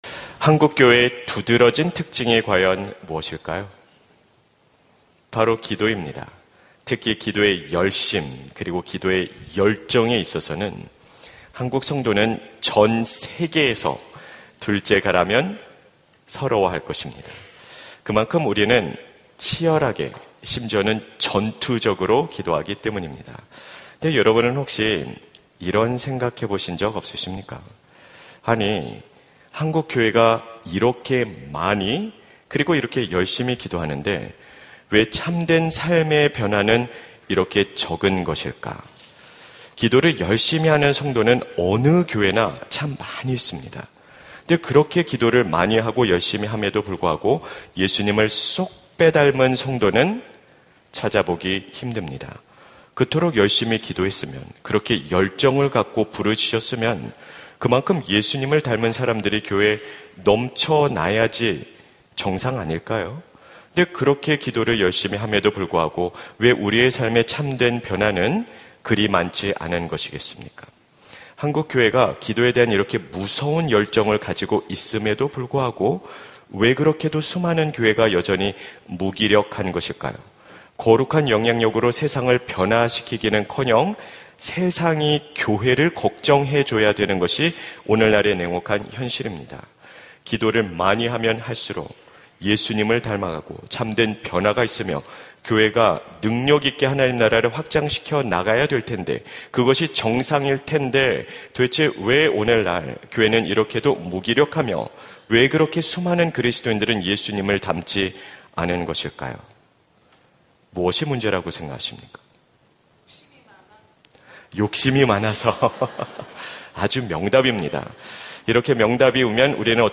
설교 : 금요심야기도회 주님 앞으로, 당당히!